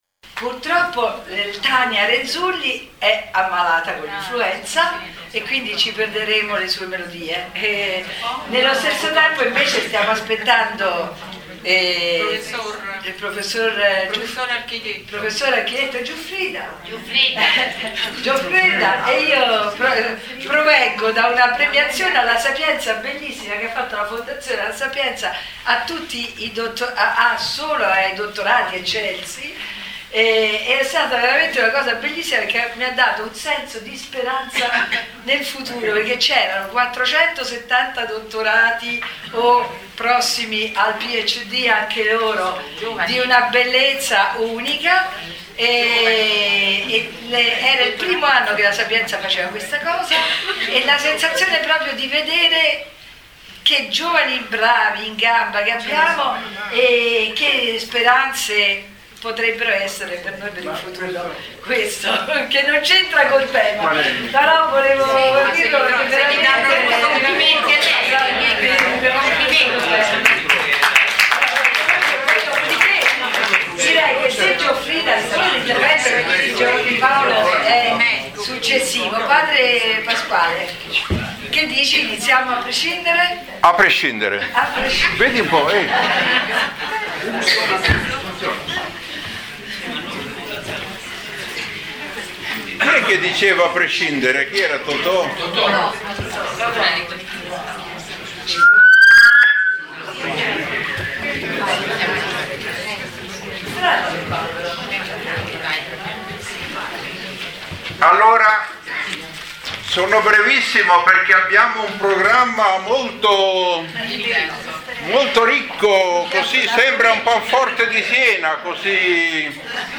Erano presenti forse novanta persone.
Presentazione: